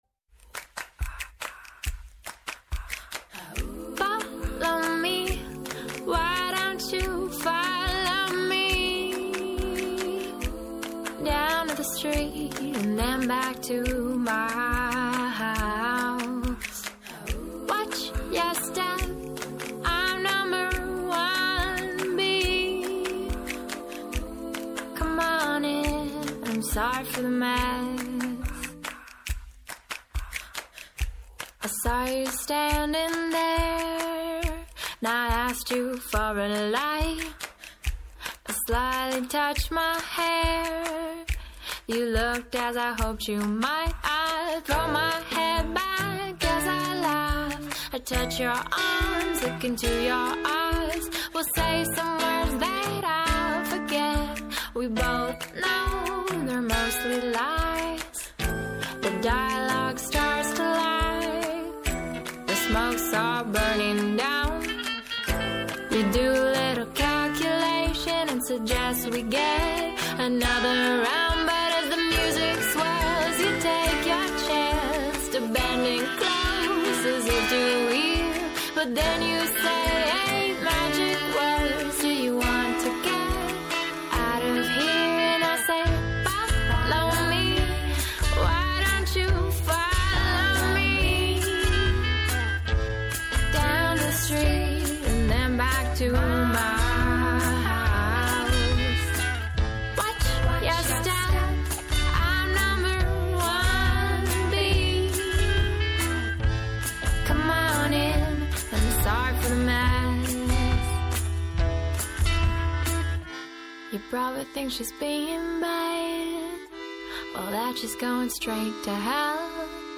Καλεσμένη απόψε η Αρχαιολόγος-Αιγυπτιολόγος
Αποχαιρετά την ημέρα που τελειώνει, ανιχνεύοντας αυτή που έρχεται. Διεθνή και εγχώρια επικαιρότητα, πολιτισμός, πρόσωπα, ιστορίες αλλά και αποτύπωση της ατζέντας της επόμενης ημέρας συνθέτουν ένα διαφορετικό είδος μαγκαζίνου με στόχο να εντοπίσουμε το θέμα της επόμενης ημέρας.